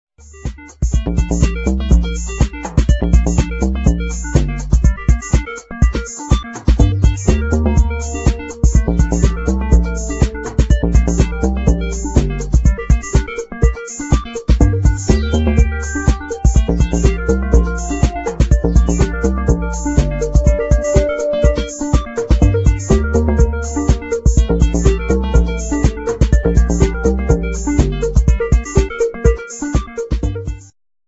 2000 exciting medium voc.